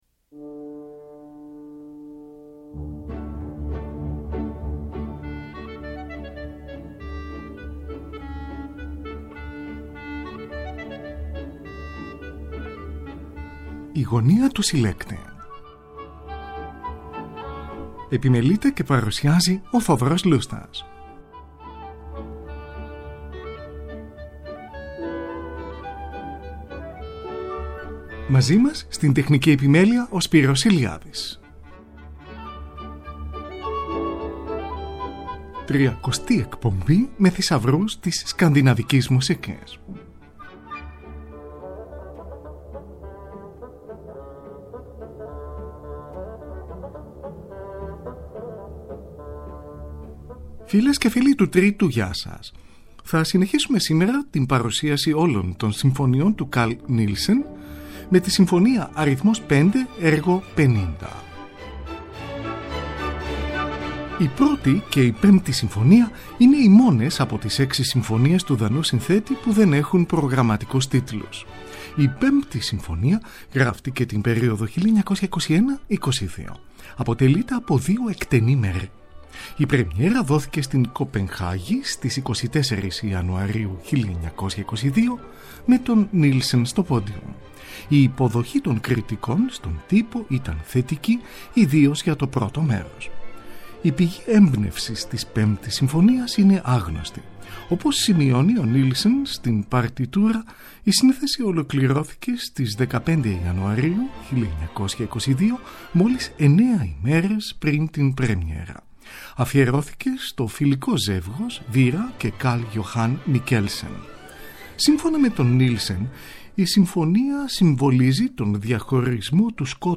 Σόλο κλαρινέτο
Carl Nielsen: σονάτα για βιολί και πιάνο αρ.2, έργο 35.